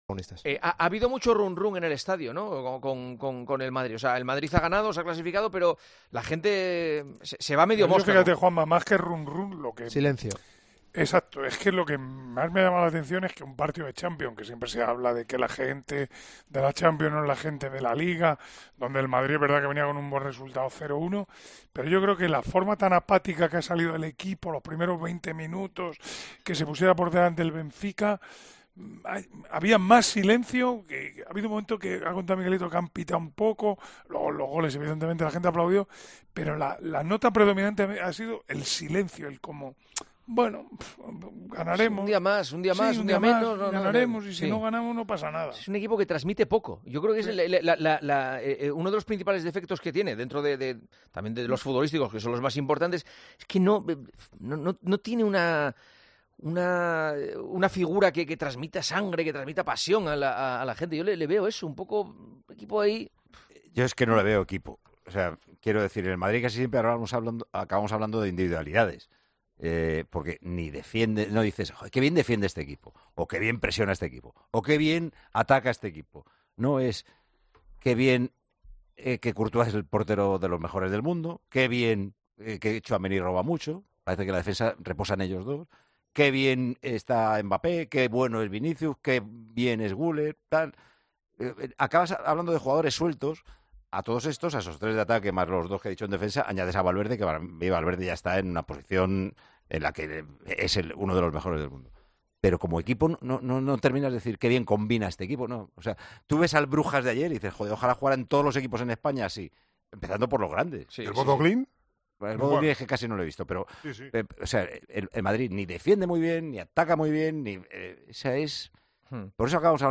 Durante el programa 'El Partidazo de COPE' con Juanma Castaño, el análisis general apuntó a que la nota predominante en el ambiente fue el "silencio".